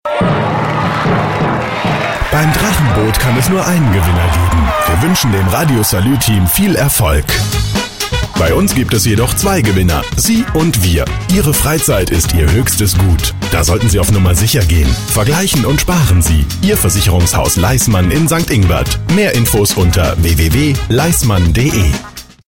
Sprecher deutsch für Werbung, Off, Hörspiel, Hörbuch, etc.
Sprechprobe: Industrie (Muttersprache):
german voice over artist